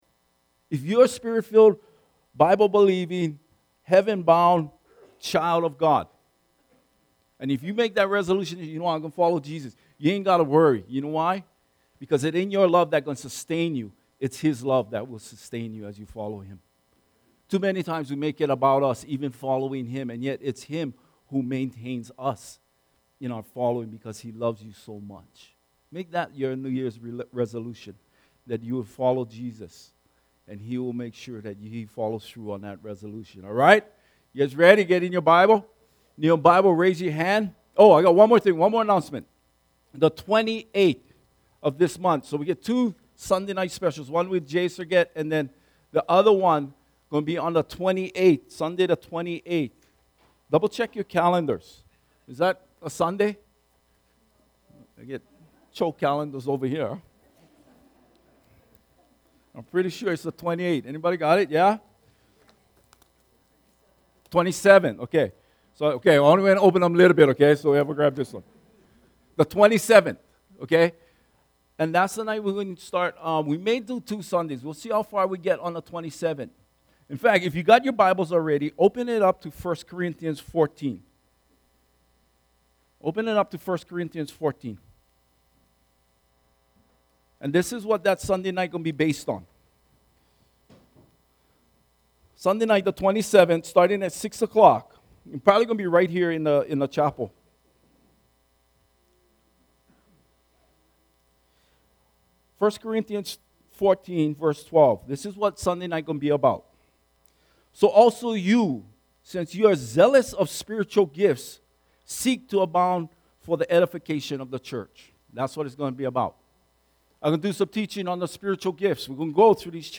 Topical Sermons - Imiola Church